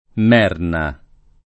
[ m $ rna ]